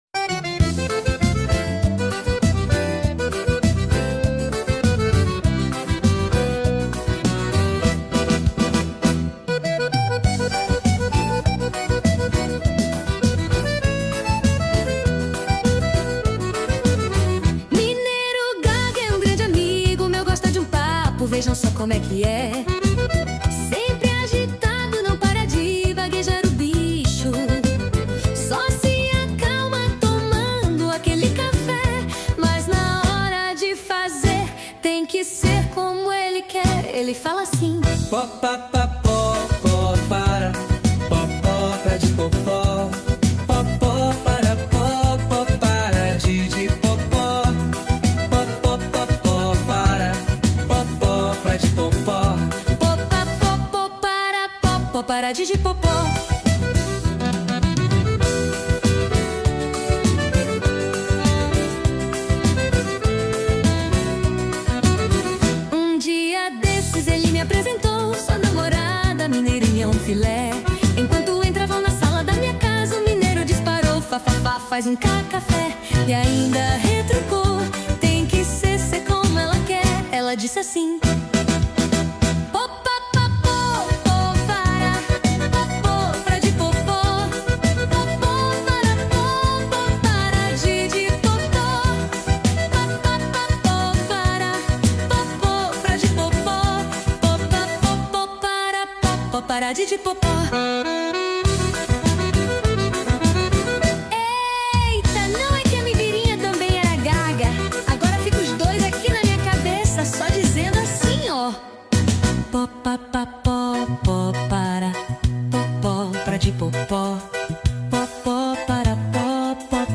Intérprete: IA